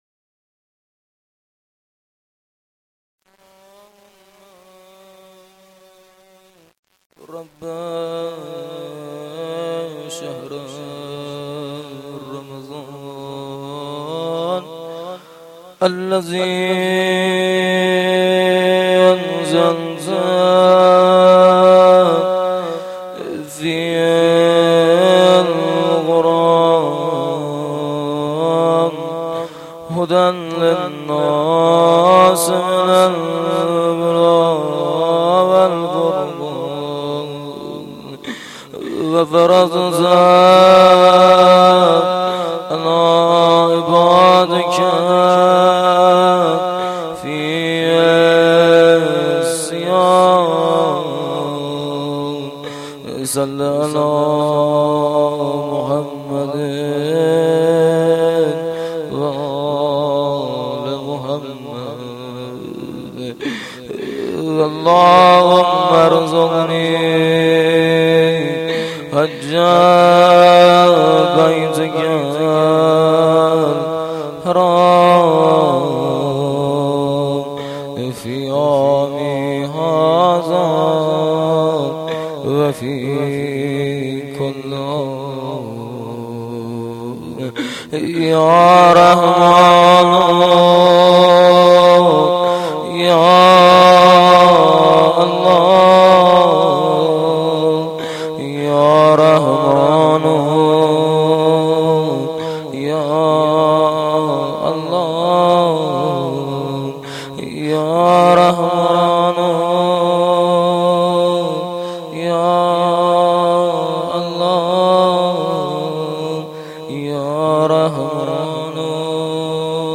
مناجات
monajat-Rozatol-Abbas-Ramezan93-sh4.mp3